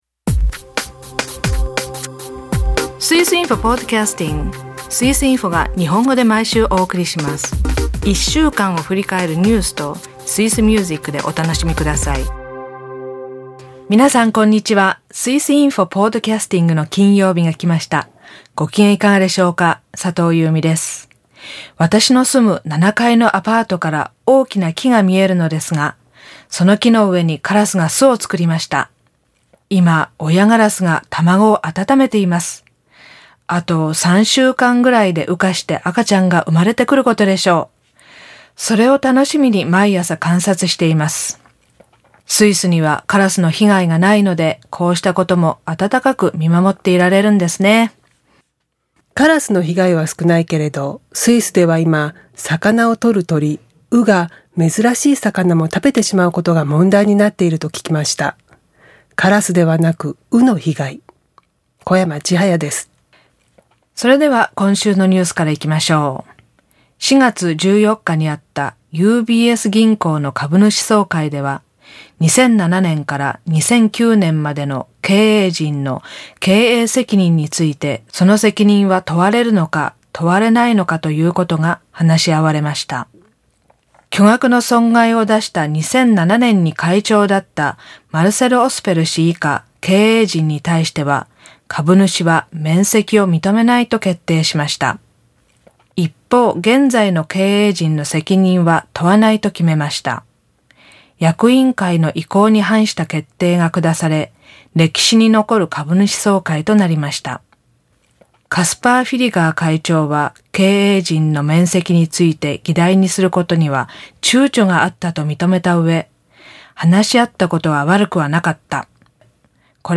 今週は経済ニュースからUBS株主総会とマネーロンダリングについて。歌はスイスの4つ目の言語ロマンシュ語の「Cun ni senza」。朗読ではウーリの旅立ちのシーンをどうぞ。